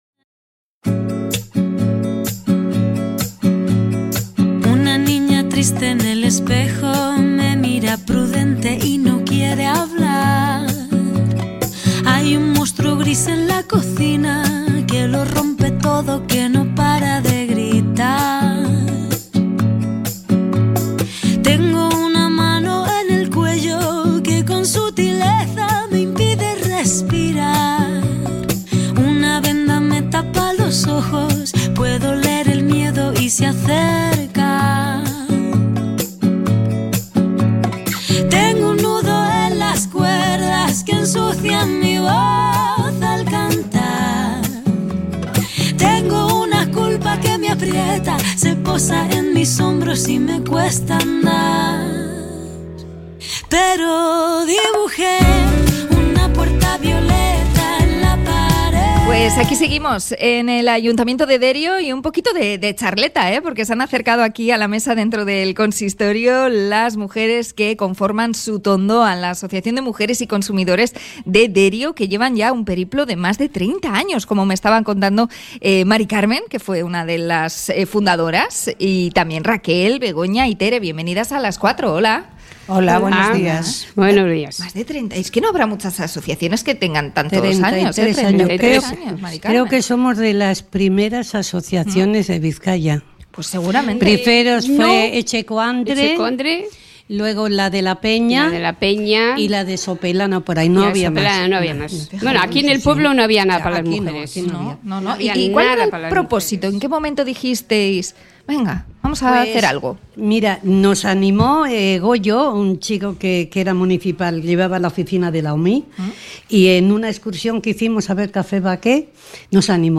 Entrevista a la asociación Sutondoan de mujeres de Derio